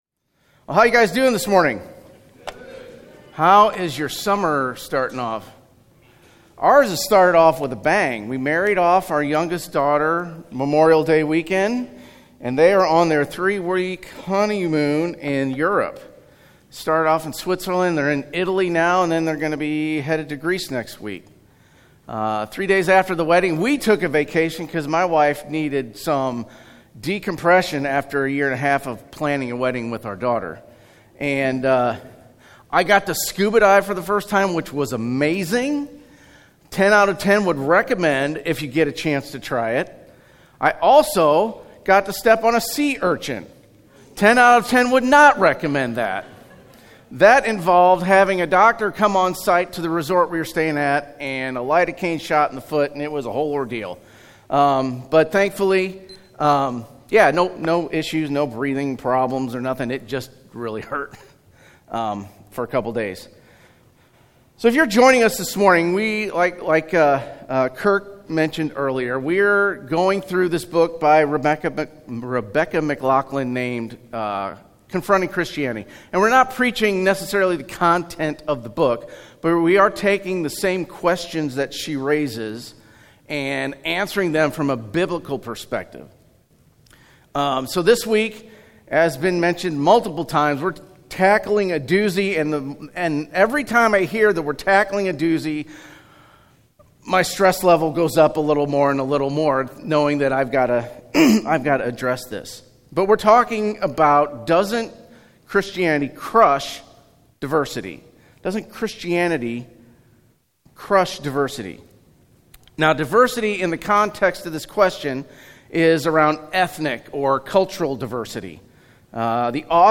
The June 2023 Sermon Audio archive of Genesis Church.